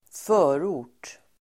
förort substantiv, suburb Uttal: [²f'ö:ro:r_t el. -or_t:] Böjningar: förorten, förorter Synonymer: förstad Definition: förstad, ytterområde Sammansättningar: betongförort (concrete suburb), förorts|bebyggelse (suburban building)